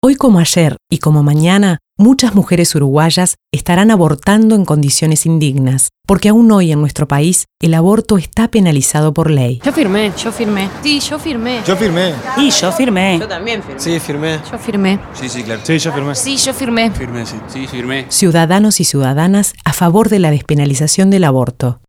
Escuchar :: Spot Radial I 28/9/07